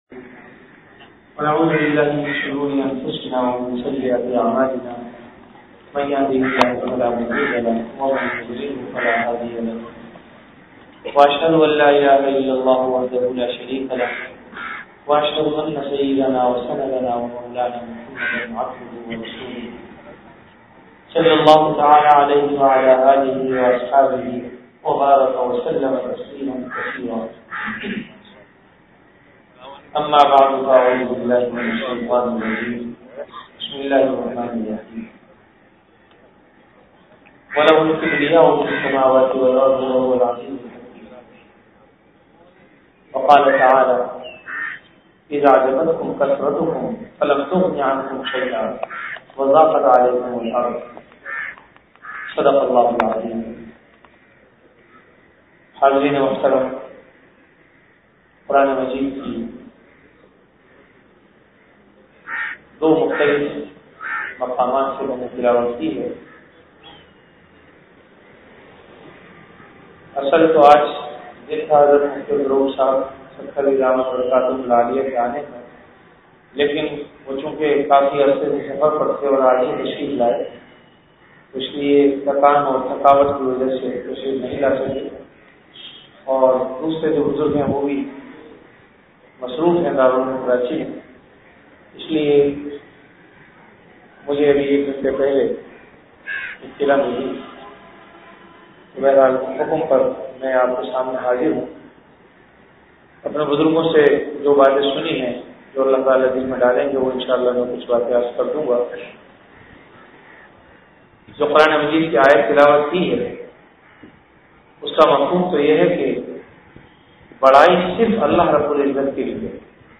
Bayanat · Jamia Masjid Bait-ul-Mukkaram, Karachi
Event / Time After Asar Prayer